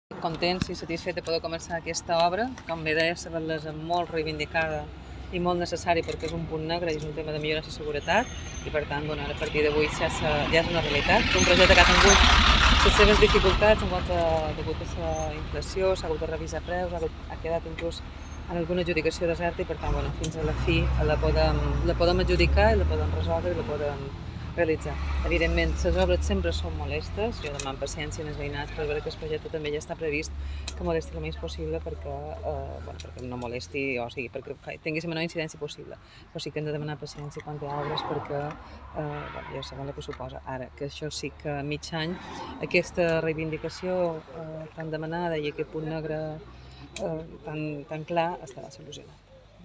Declaracions